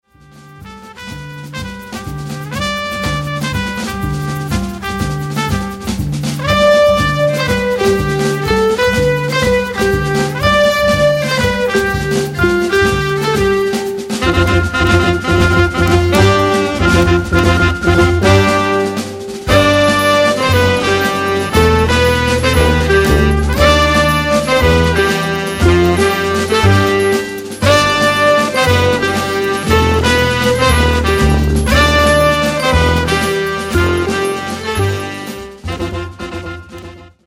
Alento de vangarda para a música tradicional
Nove músicos cunha variedade tímbrica sorprendente